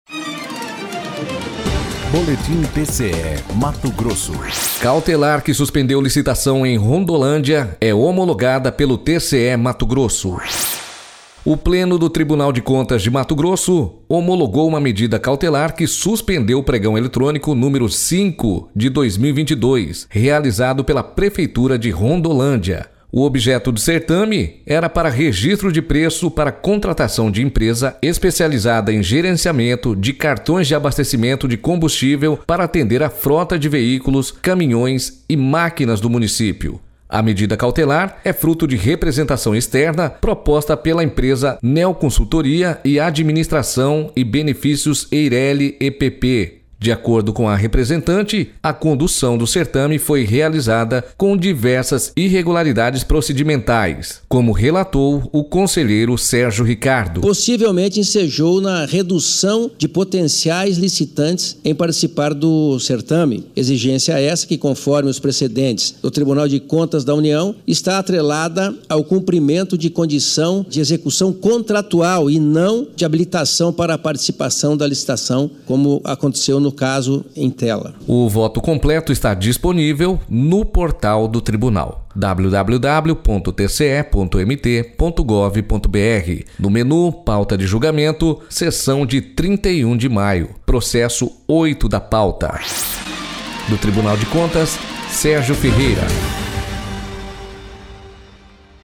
Sonora: Sérgio Ricardo – conselheiro do TCE-MT